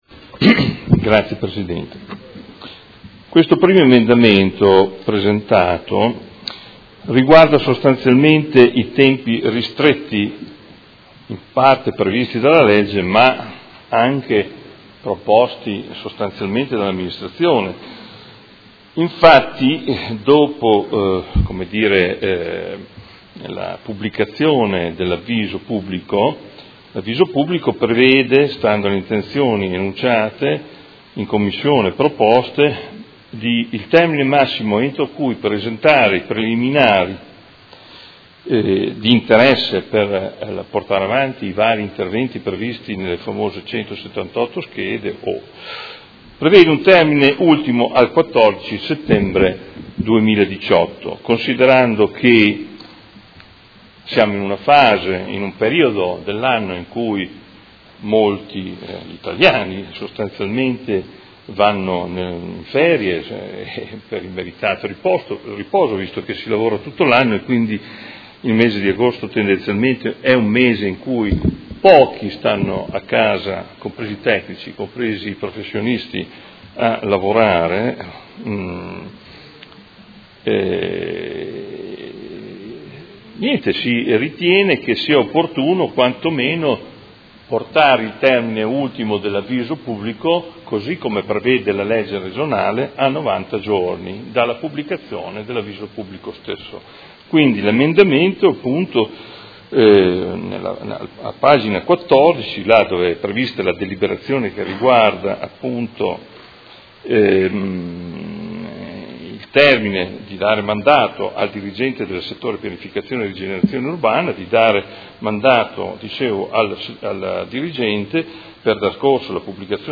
Seduta del 28/06/2018. Presenta emendamento Prot. Gen. 97733 firmato dal Gruppo Forza Italia